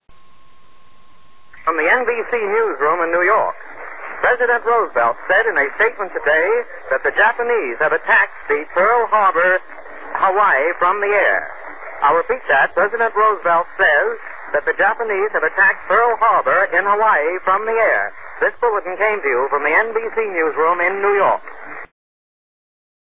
NEWS REPORT.